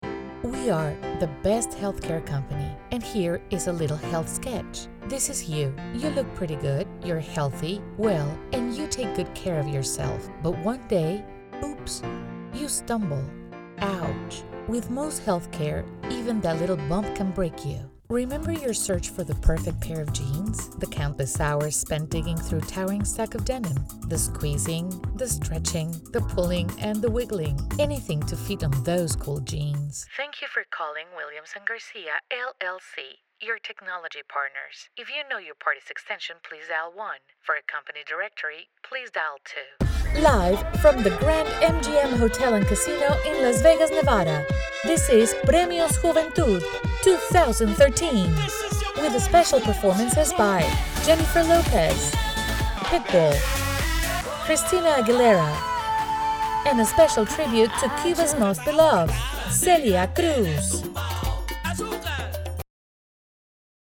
Spanish, Female, Voice Over, Bilingual english/Spanish, neutral accent, elegant. colorful, narration, commercials, e-learning, Corporate, training
Sprechprobe: Sonstiges (Muttersprache):
Spanish/English (light latino accent) with 20+ years in the industry.